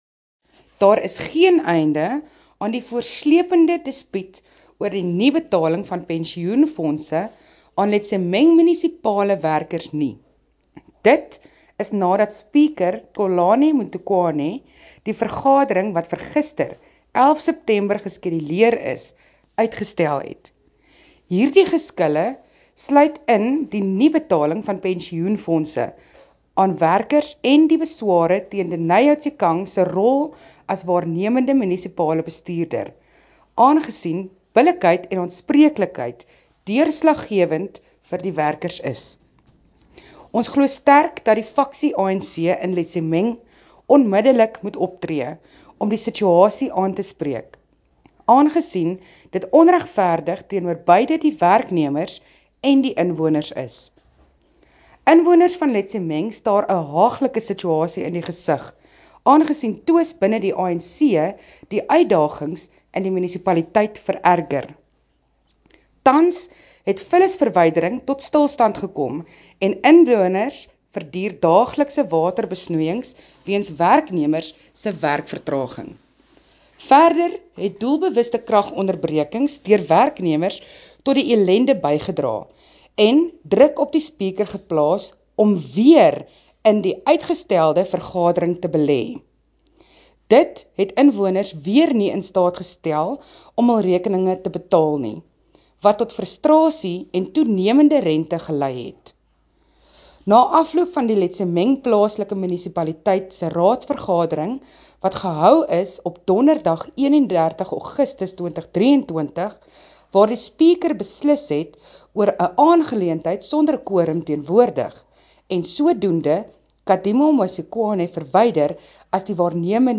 Note to editors: Please find attached an English soundbite by Cllr Thabo Nthapo,